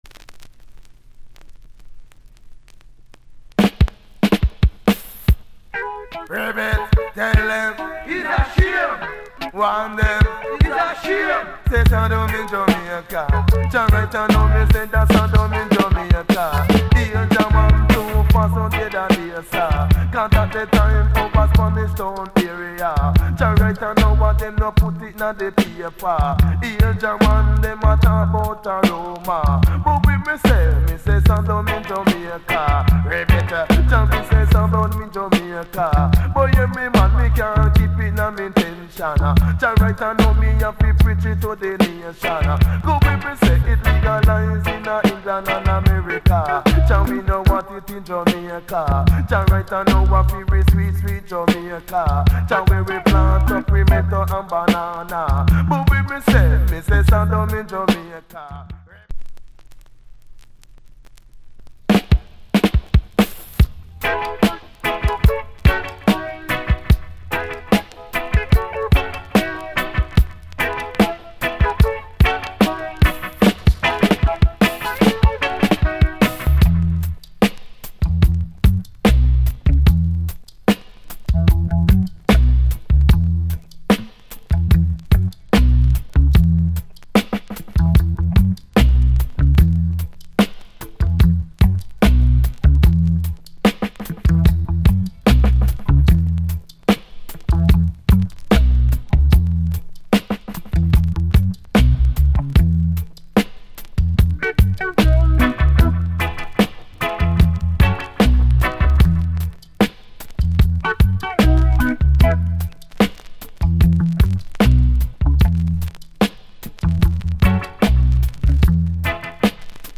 Genre Reggae80sEarly / Male DJ